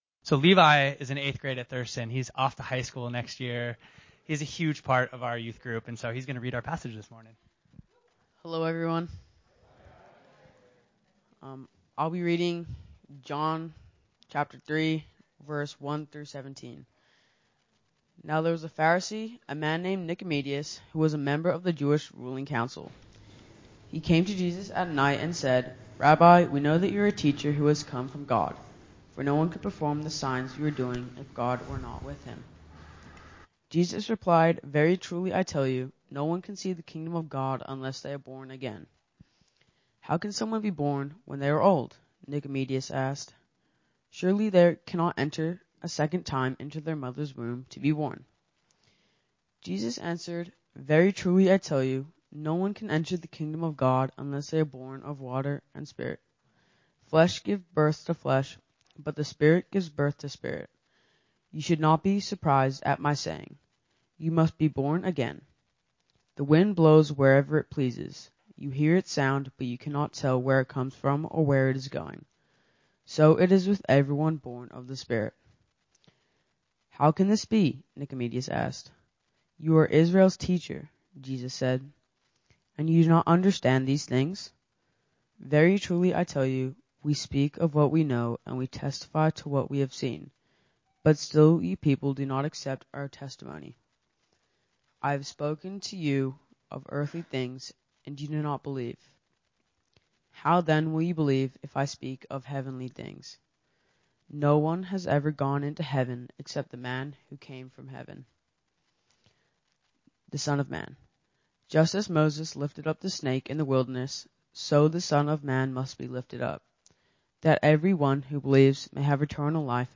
Series: Ordinary Time